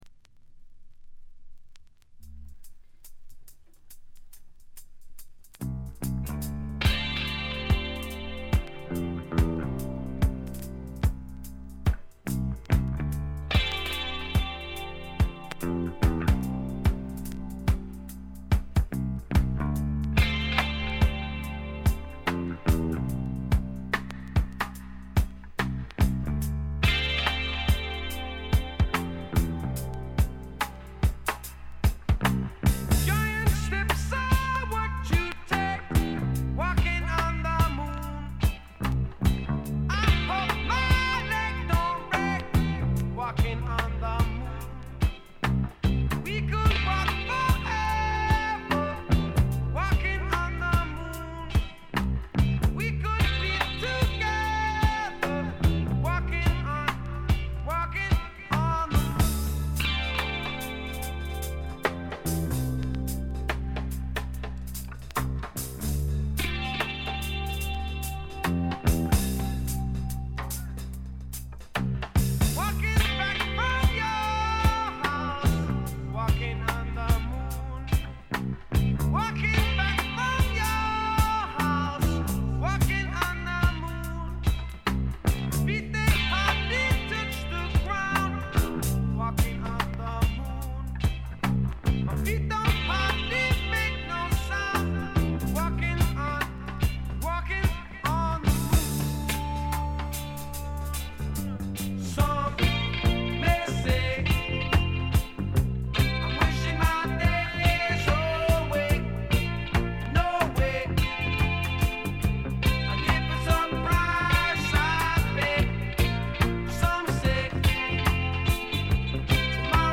C1フェードアウトの消え際にプツ音。
試聴曲は現品からの取り込み音源です。
Recorded At - Surrey Sound Studios